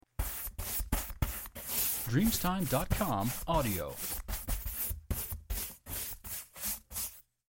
Brucia
• SFX